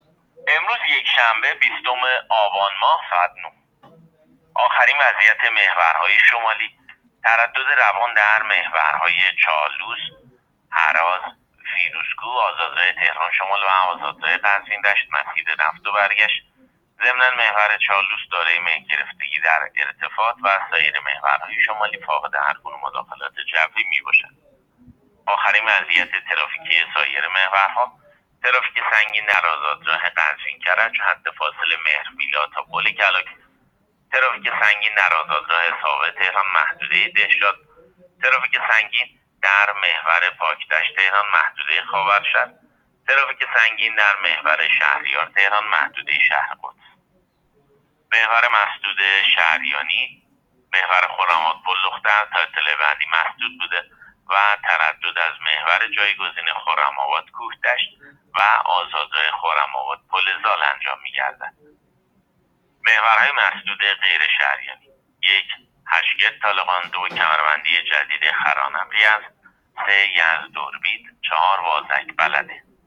گزارش رادیو اینترنتی از آخرین وضعیت ترافیکی جاده‌ها تا ساعت ۹ روز ۲۰ آبان؛